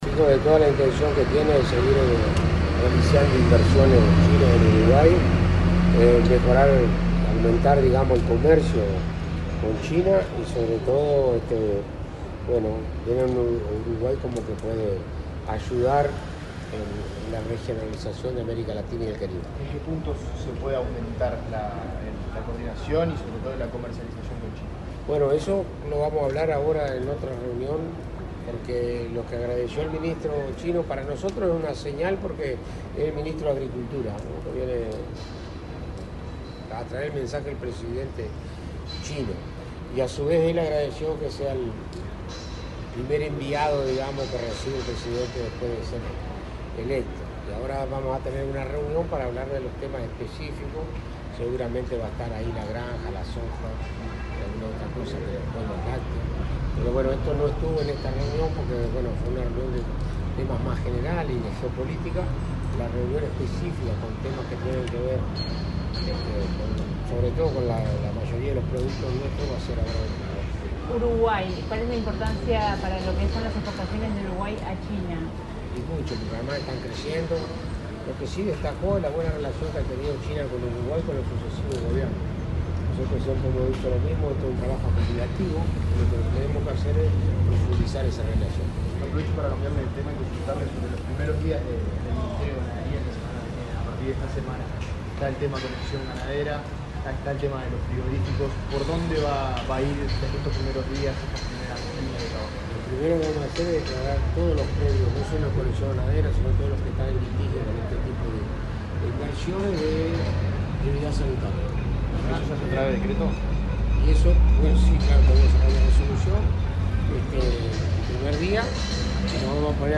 Declaraciones del ministro de Ganadería, Alfredo Fratti
Declaraciones del ministro de Ganadería, Alfredo Fratti 02/03/2025 Compartir Facebook X Copiar enlace WhatsApp LinkedIn El ministro de Ganadería, Alfredo Fratti, dialogó con la prensa en el Palacio Estévez, luego de participar en una reunión que el presidente de la República, profesor Yamandú Orsi, mantuvo con el titular de Agricultura y Asuntos Rurales de la República Popular China, Han Jun.